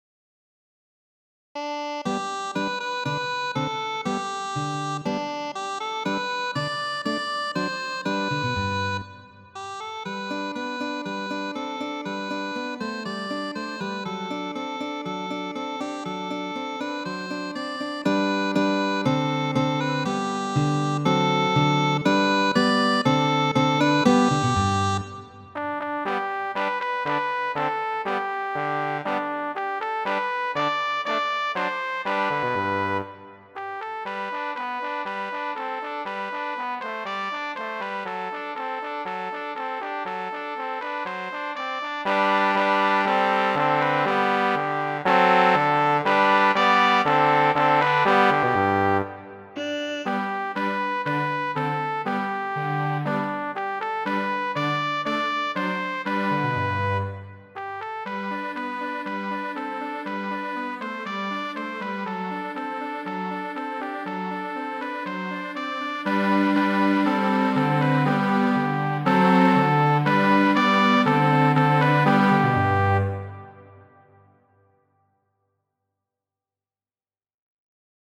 Kajero 46 ~ Kajeroj ~ En PDF Muziko: La bona kamarado , infana germana kanto.